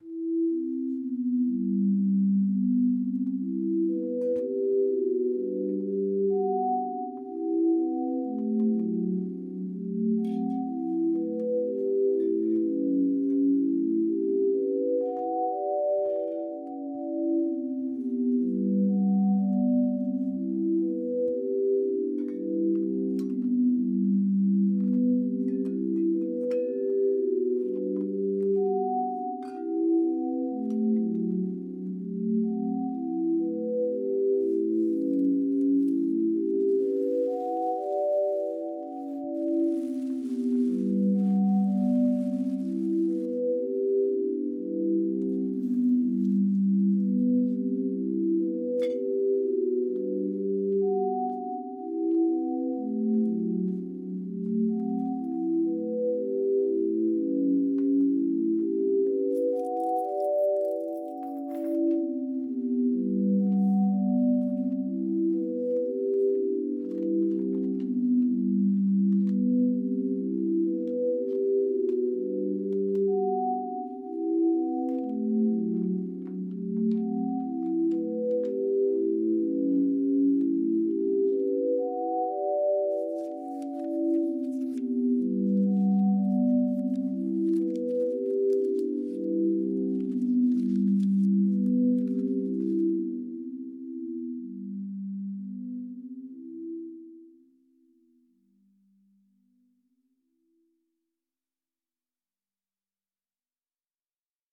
Out with the drones, in with the lush pads and synth lines.